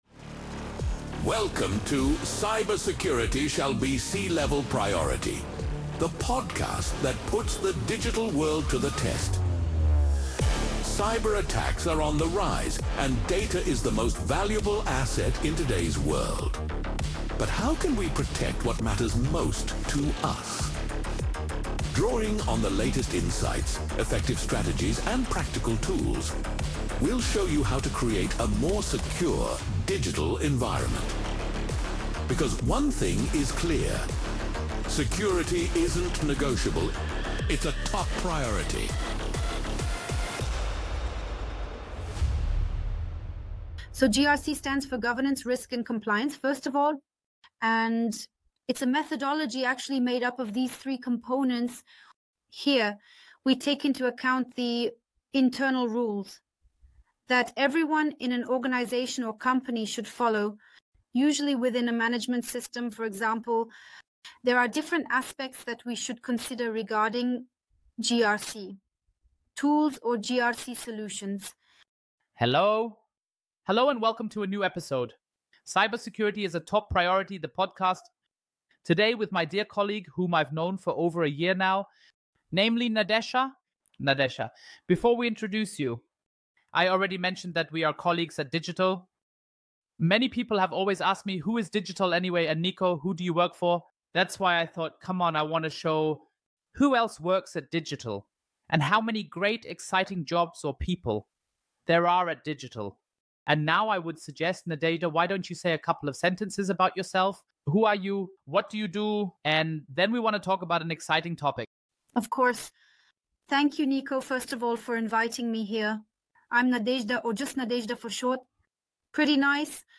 Webpage ____________________________________________ 🚨 This Podcast is translated from the original content Cybersecurity ist Chefsache using AI technology to make them accessible to a broader audience.